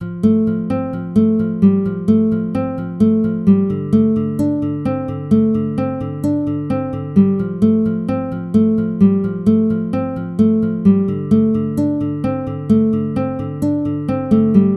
寒冷的吉他循环
Tag: 130 bpm Acoustic Loops Guitar Acoustic Loops 2.48 MB wav Key : F